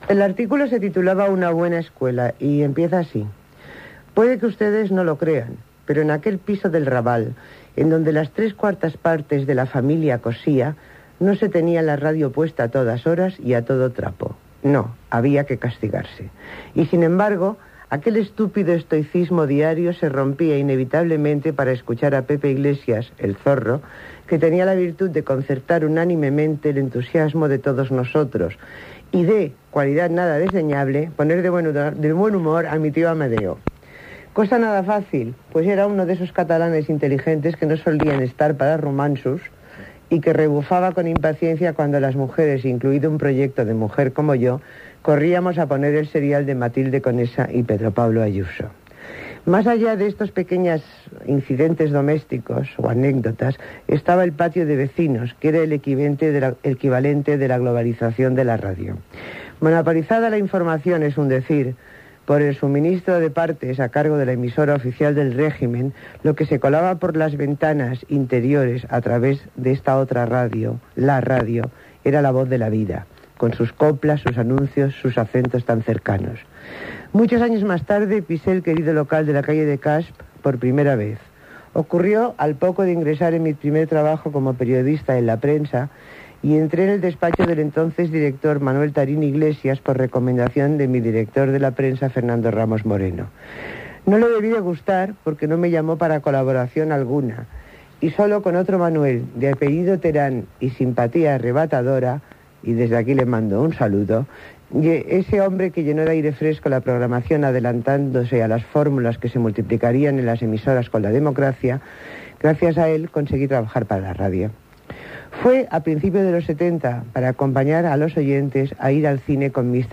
Maruja Torres llegeix l'article "Una buena escuela" que va publicar al diari "El País" amb motiu del 75è aniversari de Ràdio Barcelona, l'any 1999.
Divulgació